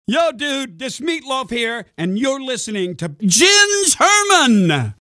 Am 19. April 2010 erscheint sein neues Album „Hang Cool, Teddy Bear“. Um ein wenig die Werbetrommel für seine elfte Platte zu rühren, schaute Meat Loaf bei BB Radio vorbei und stellte sich bestens gelaunt vielen Fragen.